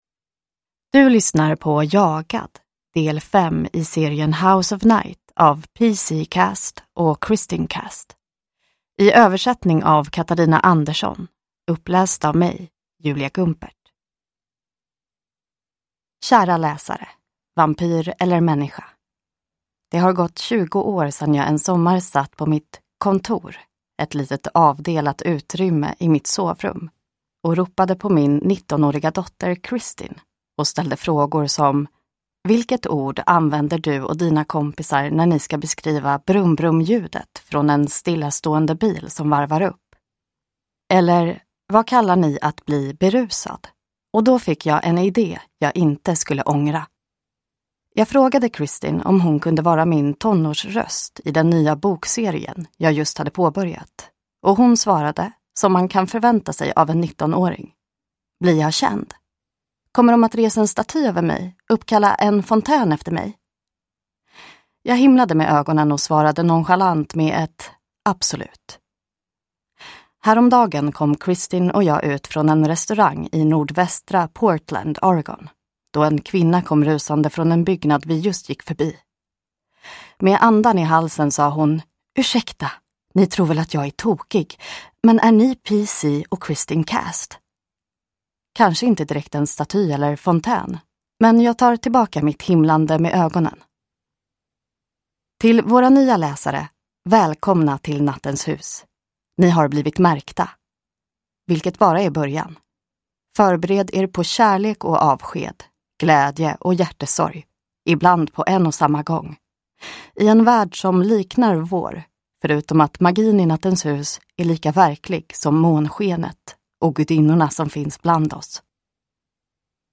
Jagad – Ljudbok